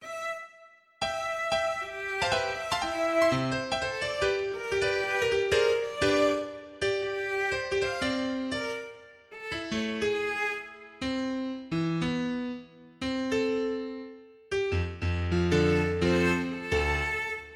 Продукт уже можно генерировать, но сейчас его качество сложно назвать приемлемым, сеть показывает лишь базовое понимание гармонических сочетаний и ритма.